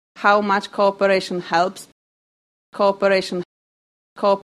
Non-natives typically pronounce coop- as it’s written, with a single o quality and no w. Unfortunately, this is likely to sound like corp- as pronounced in England and Wales. As a result, non-native cooperation can sound rather like native corporation:
cooperation_nonnative.mp3